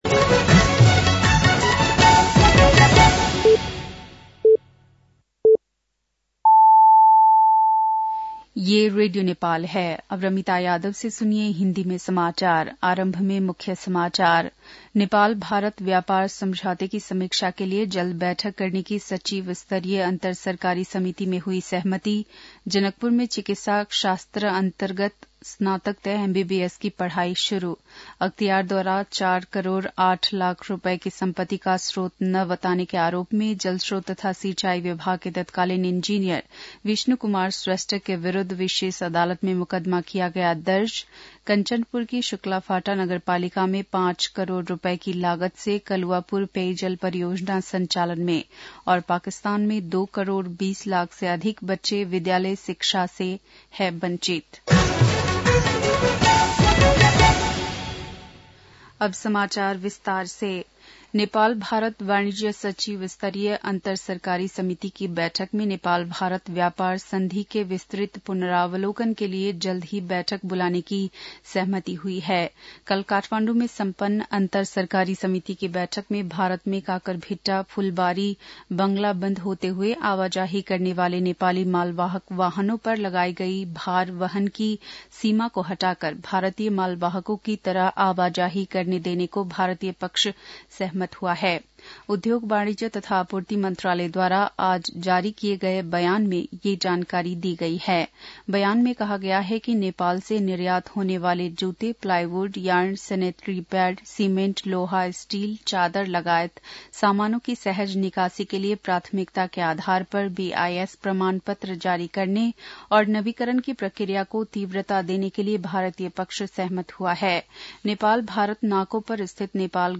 बेलुकी १० बजेको हिन्दी समाचार : २९ पुष , २०८१
10pm-hindi-news-9-28.mp3